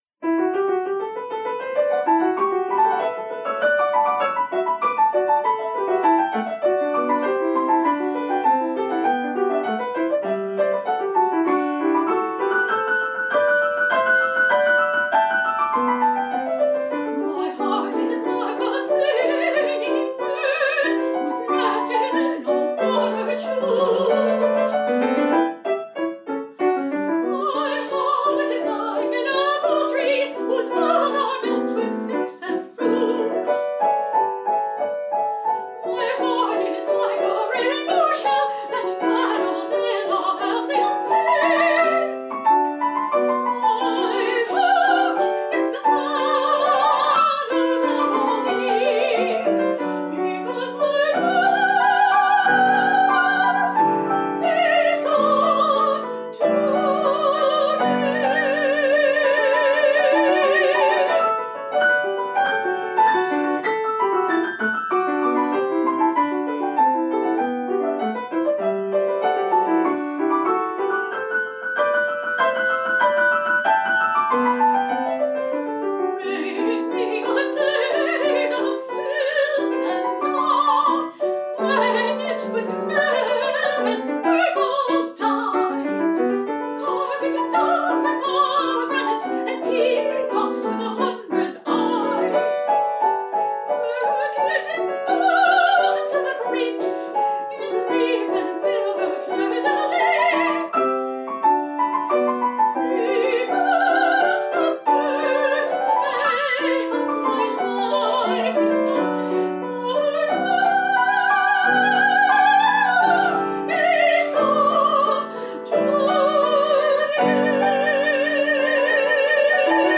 for medium high voice & piano